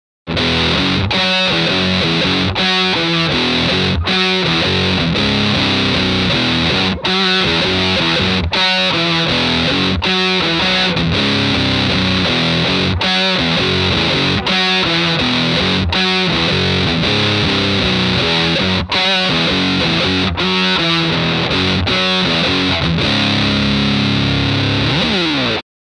Vintage Style Octave Effect with Distortion
- Custom Distortion sound (after octave)
Demo with Humbucker Pickup 2